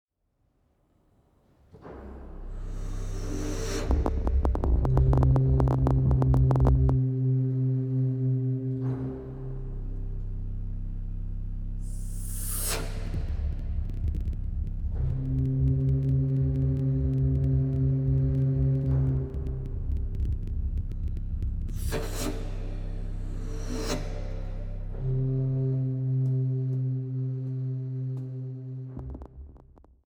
Neue Musik für Flöte und Orgel (II)
Flöte
Orgel